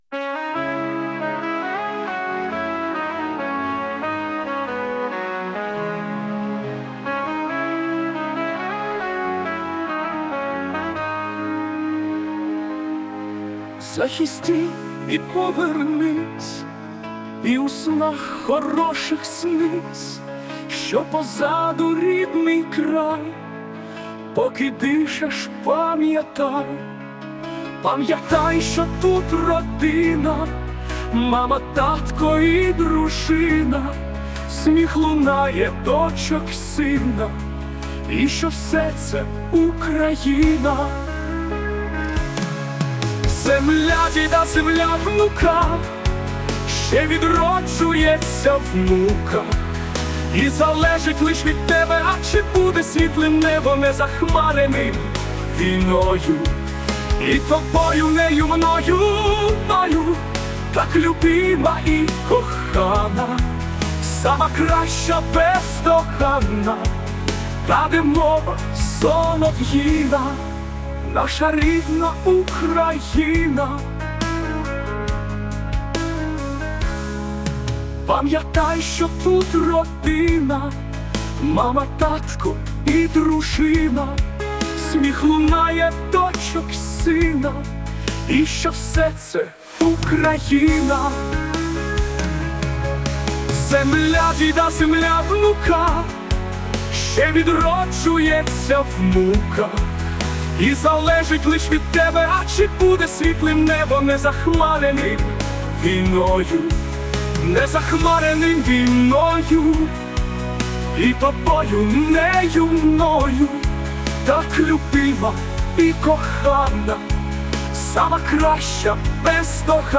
ТИП: Пісня
ВИД ТВОРУ: Пісня